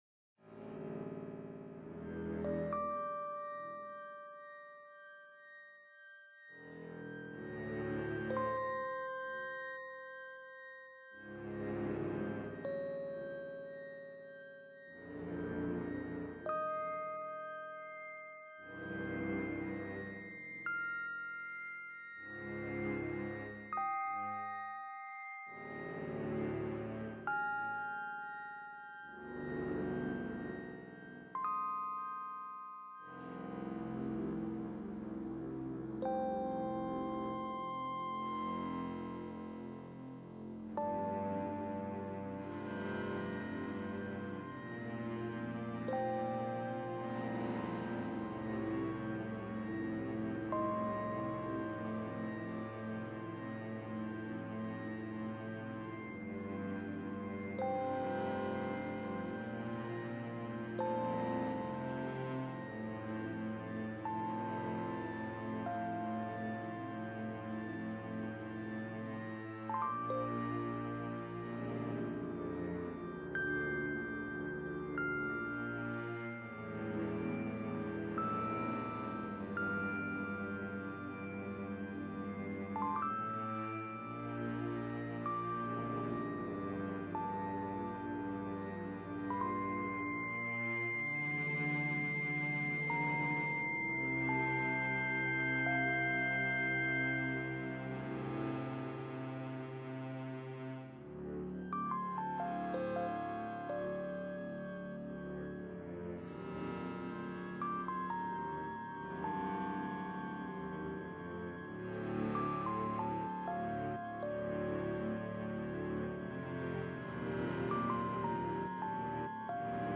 BGM Music